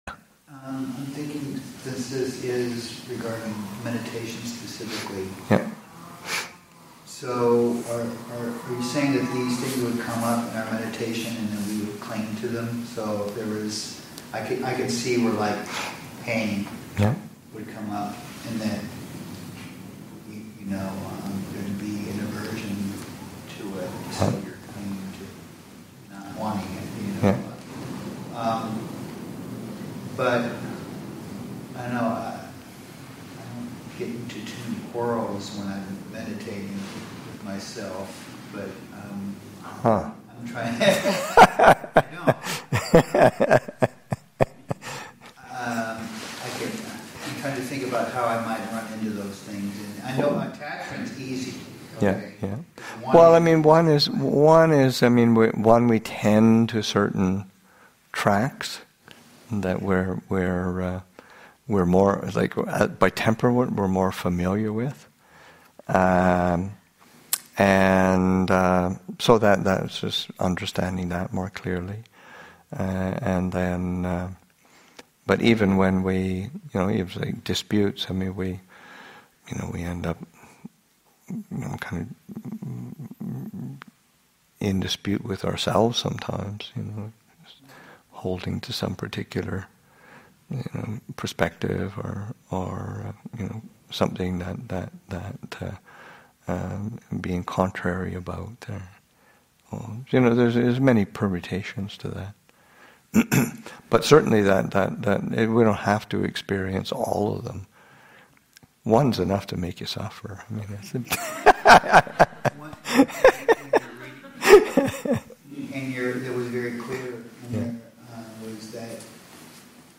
3. Discussion about the meaning of quarrels in meditation and in daily life.